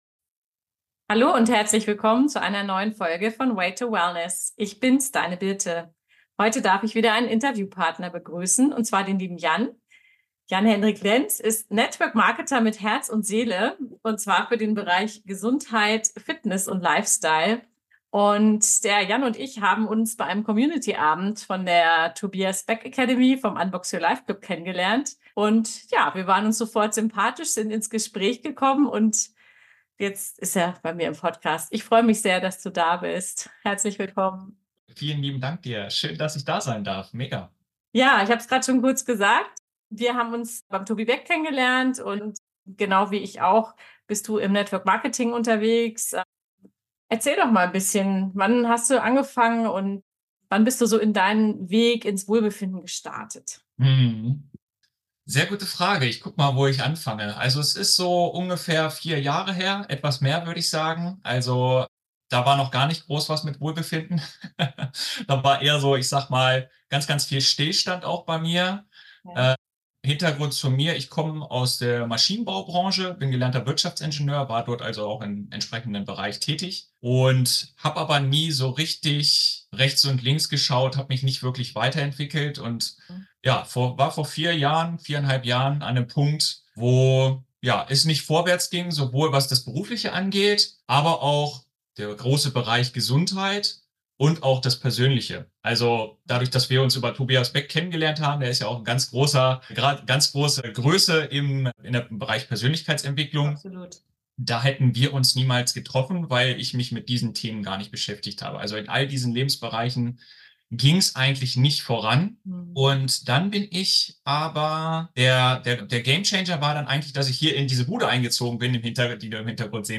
Vom Ingenieur zum Inspirationsgeber - Interview